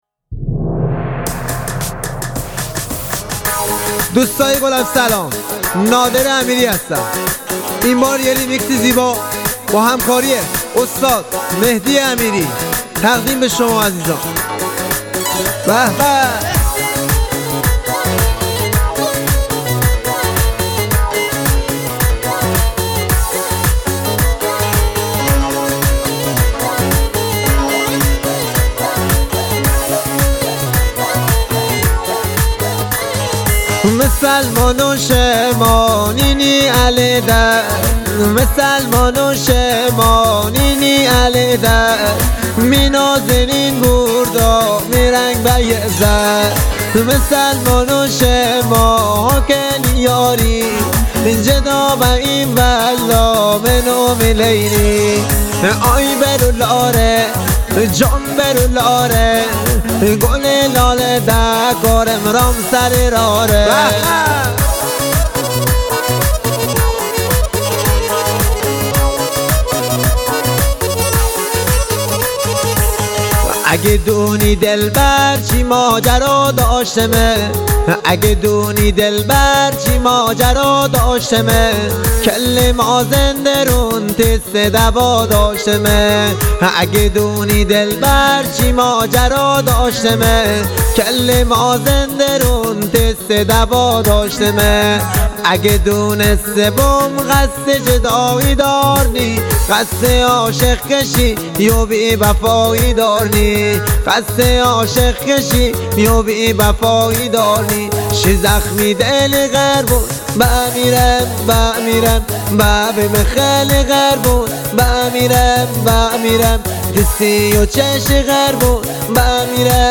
ریتمیک ( تکدست )
ریمیکس مازندرانی